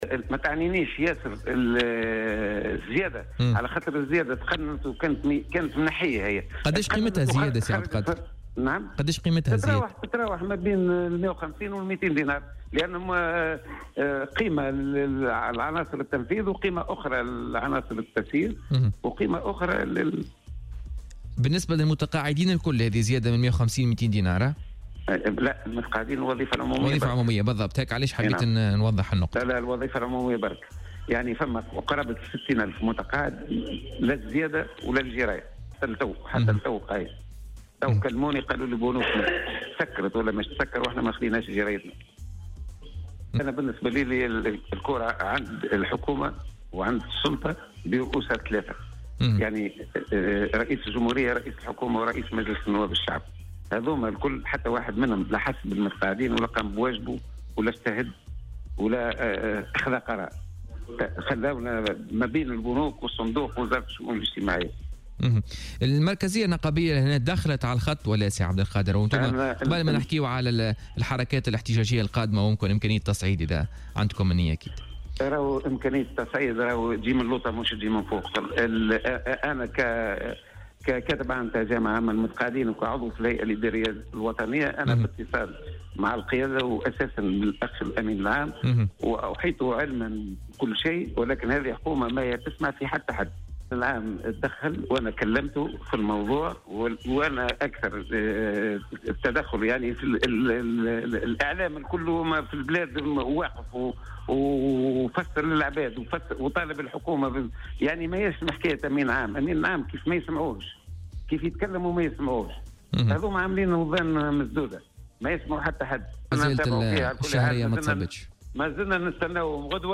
وأضاف في مداخلة له اليوم في برنامج "بوليتيكا" اليوم الخميس أنهم قد يضطرون إلى التصعيد في صورة تواصل التأخر في صرف مستحقاتهم وعدم الاستجابة لبقية مطالبهم.